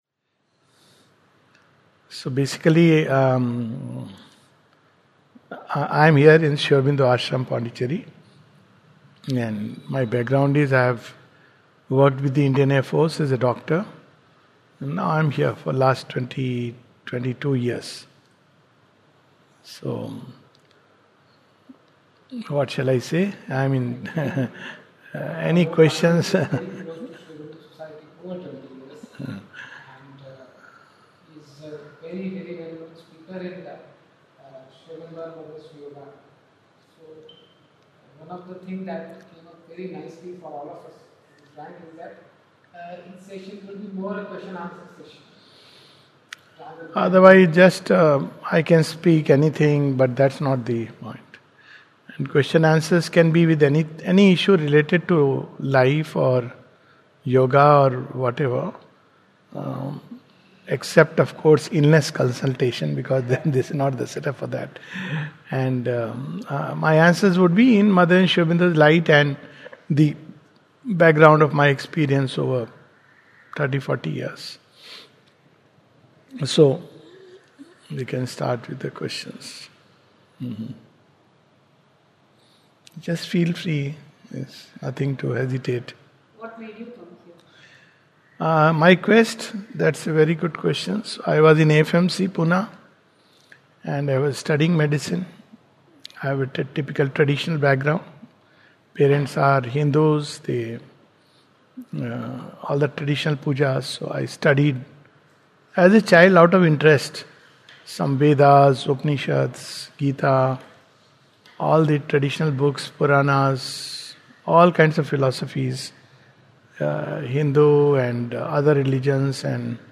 This is an interactive session at Matruniketan on questions related to finding the soul, journey of life, karma, detachment, duties, God etc.